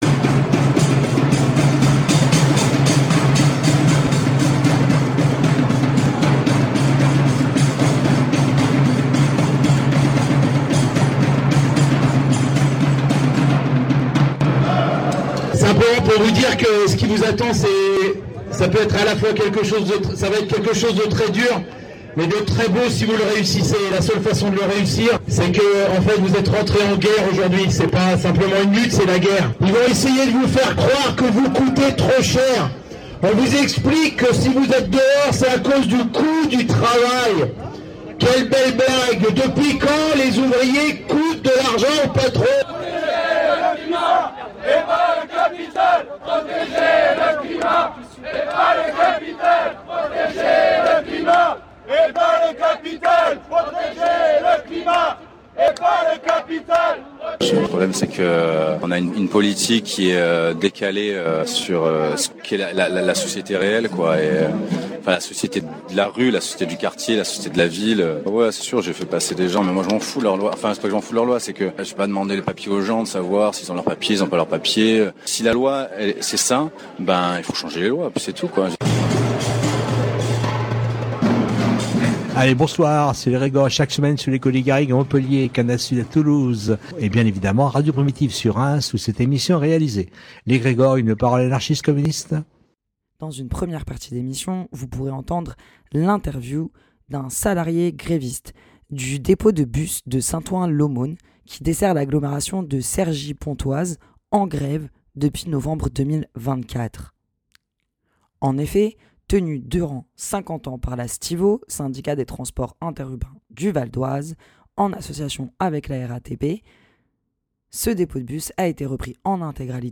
Nous vous diffusons donc dans une première partie d’émission, un entretien réalisé avec l’un des grévistes.
Vous entendrez donc les prises de paroles de l’assemblée générale qui se tenait gare du Nord le 21 novembre dernier et un entretien réalisé avec un agent commercial de la ligne B. classé dans : société Derniers podcasts Découvrez le Conservatoire à rayonnement régional de Reims autrement !